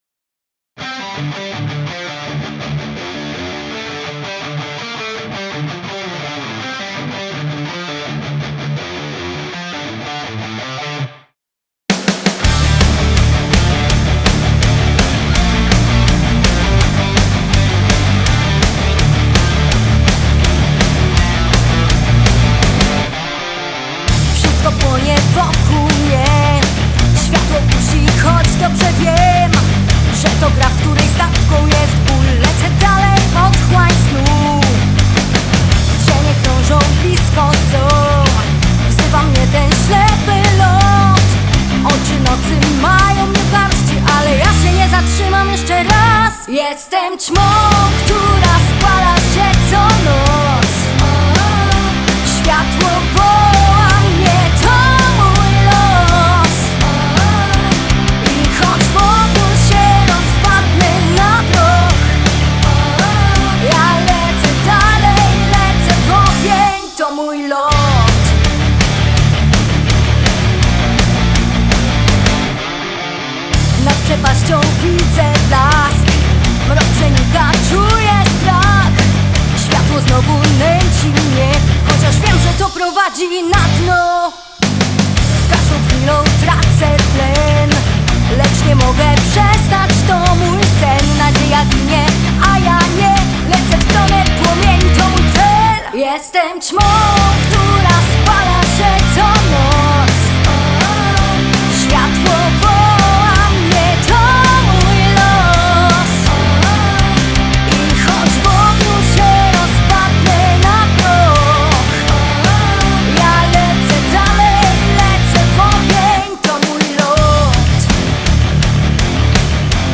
Rock & Roll
czteroosobowy zespół rockowy
Zespół Rockowy